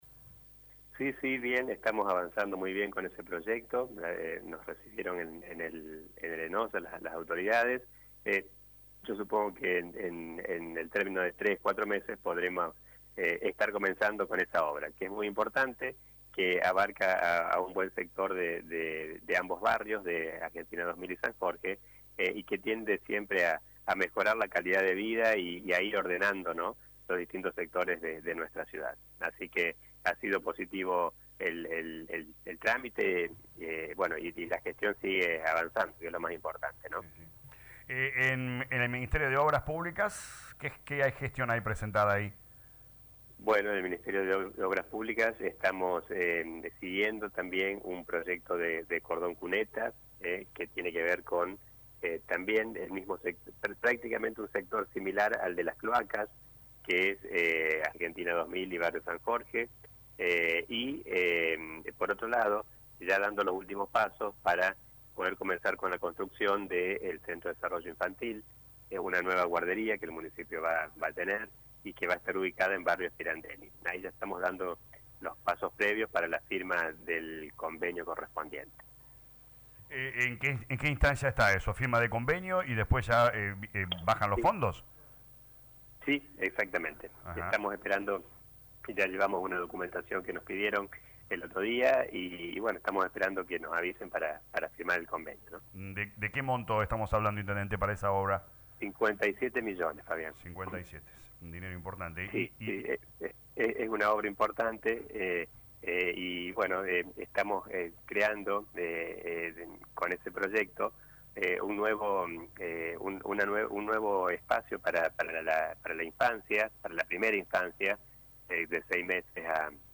Lo confirmó el intendente Municipal Dr. Gustavo Tevez en diálogo con LA RADIO 102.9 FM y agregó que bajarán los fondos una vez realizada la firma del convenio.